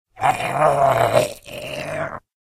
zombie_idle_8.ogg